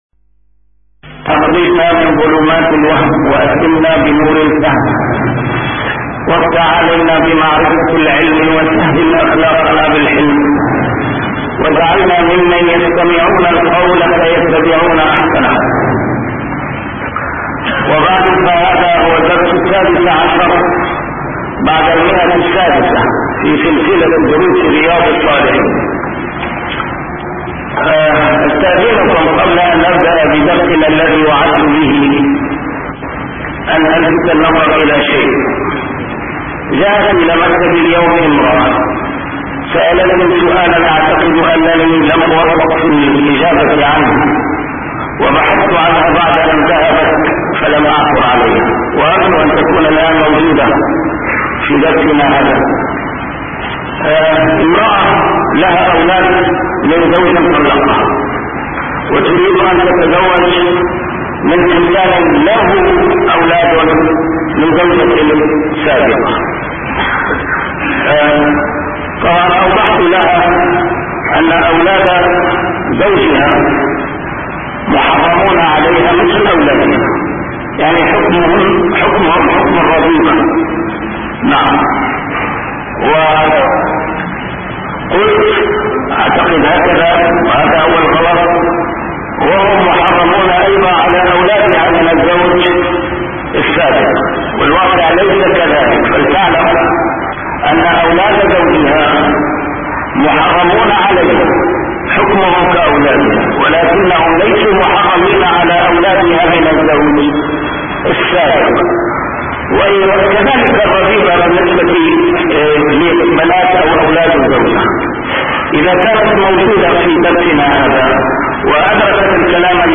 A MARTYR SCHOLAR: IMAM MUHAMMAD SAEED RAMADAN AL-BOUTI - الدروس العلمية - شرح كتاب رياض الصالحين - 616- شرح رياض الصالحين: المحافظة على ما اعتاده من الخير